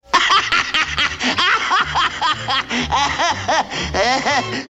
Bugs Bunny Laugh Sound Effect Free Download
Bugs Bunny Laugh